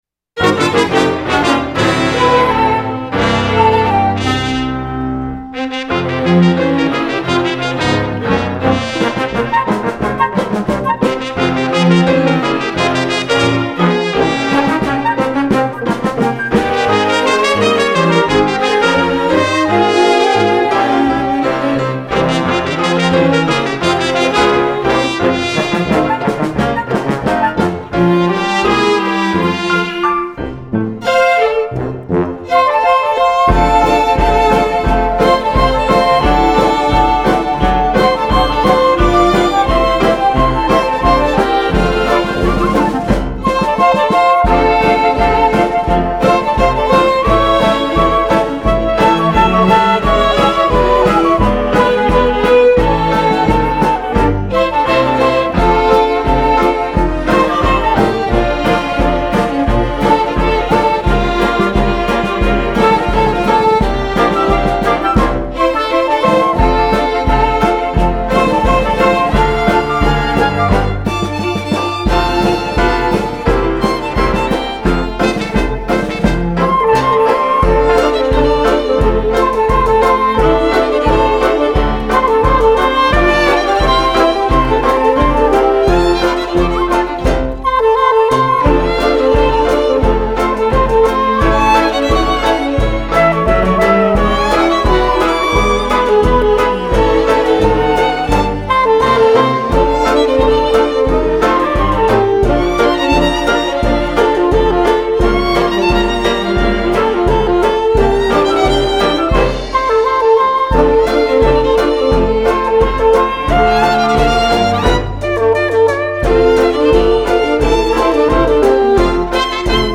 1920   Genre: Pop   Artist